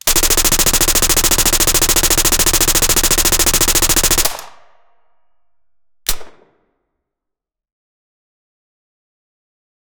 a-draco-sub-machine-gun-y4m767q7.wav